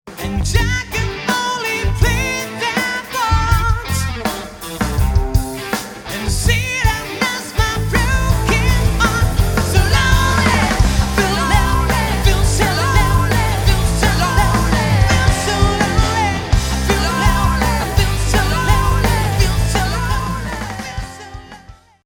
encore deux exemples d'un truc un peu plus rock que j'avais fait il y a deux semaines: console M7CL (tout dans la console) et comme on l'entend bien, déjà une bonne dose de compression sur le chant (également pas mal sur la basse en prise DI... mais rien sur la batterie); du reste ça a été mixé et enregistré à -15dB
son traité après un petit mastering et rehausse de relief